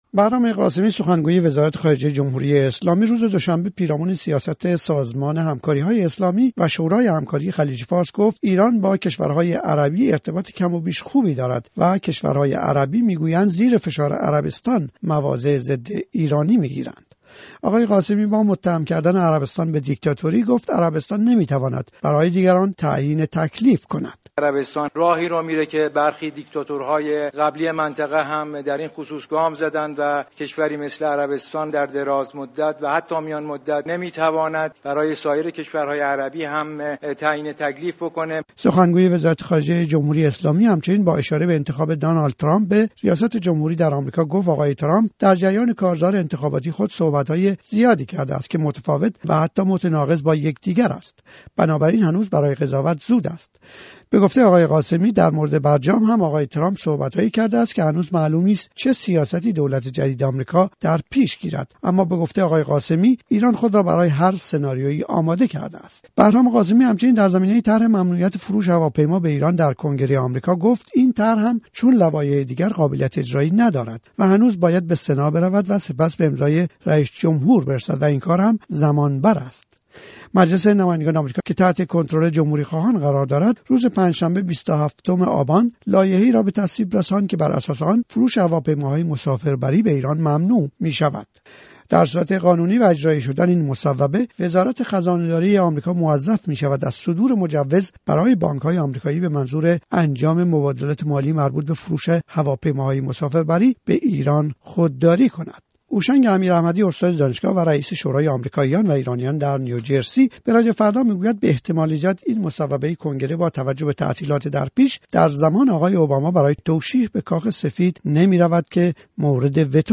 هوشنگ امیراحمدی در گفت‌وگو با رادیو فردا: پول فروش نفت پس از برجام هنوز هم به ایران پرداخت نمی‌شود